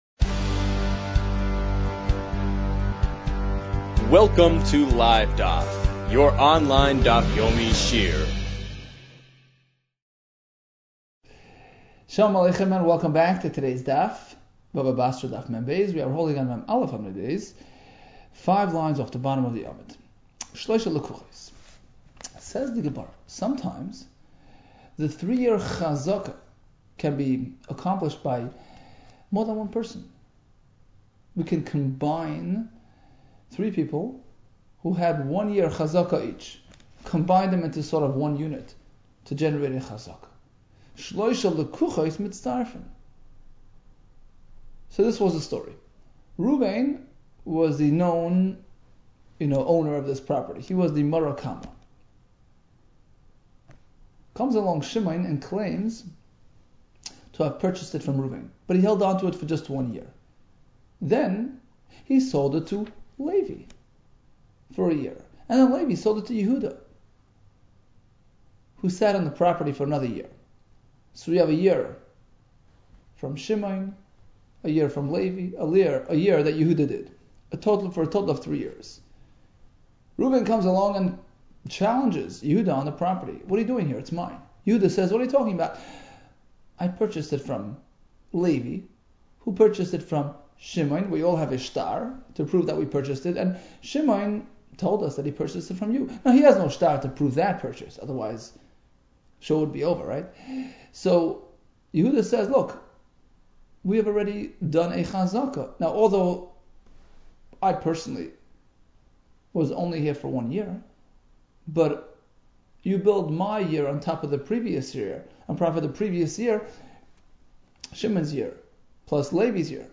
Bava Basra 42 - בבא בתרא מב | Daf Yomi Online Shiur | Livedaf